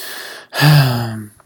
sigh.ogg